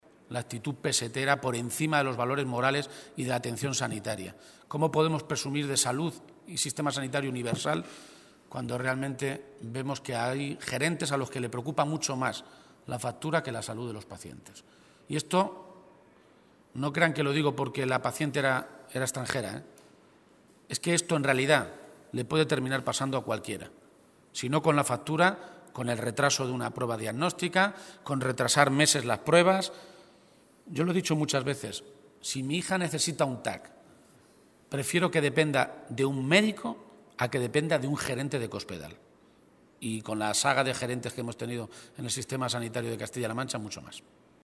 García-Page se pronunciaba de esta manera, esta mañana, en Toledo, a preguntas de los medios de comunicación, al tiempo que exigía “una reacción inmediata al Gobierno de Cospedal.
Cortes de audio de la rueda de prensa